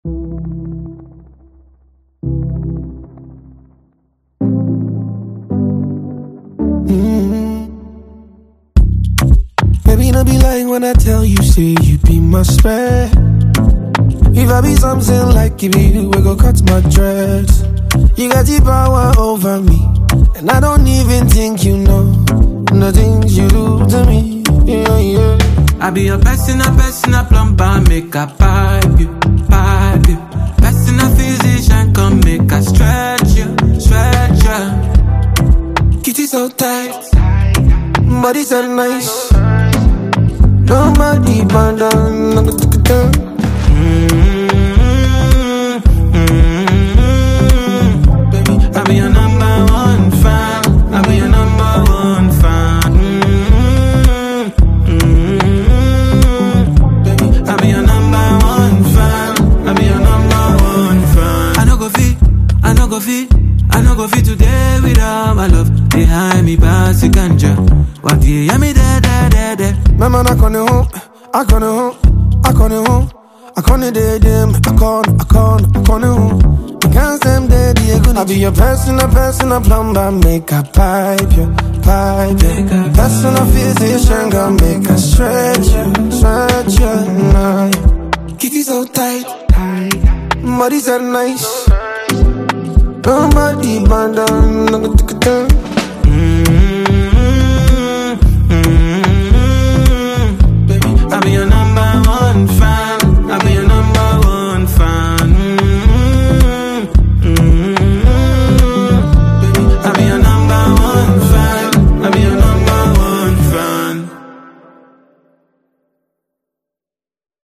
smooth and infectious Afrobeats collaboration
• Genre: Afrobeats / R&B